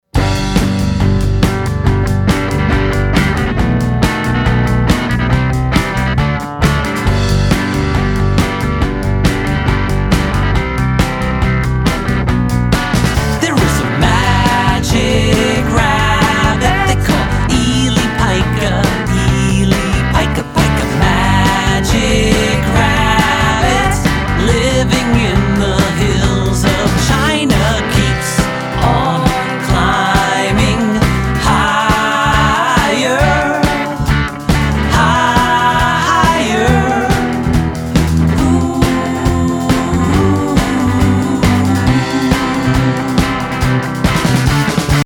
singable tunes and danceable rhythms